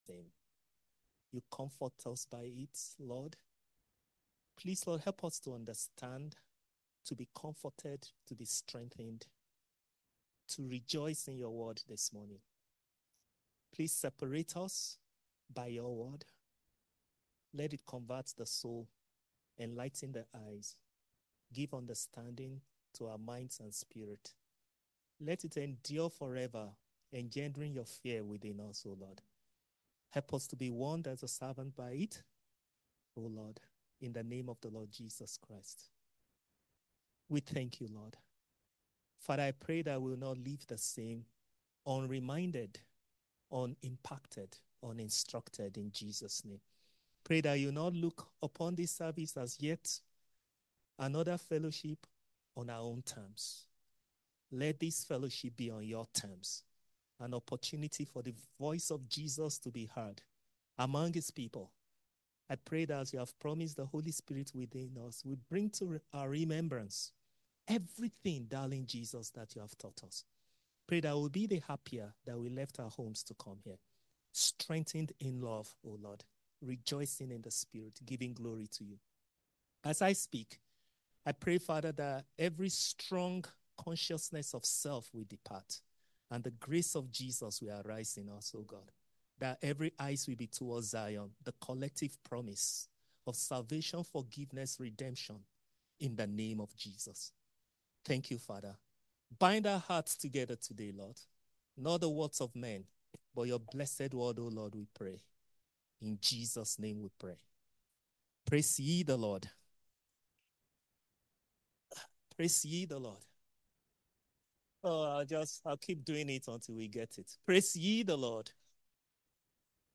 From Series: "Sermons"